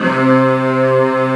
14 STRG C2-L.wav